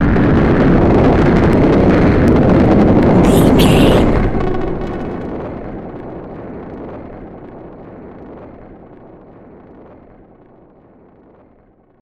In-crescendo
Atonal
tension
ominous
haunting
eerie
synth
keyboards
ambience
pads
eletronic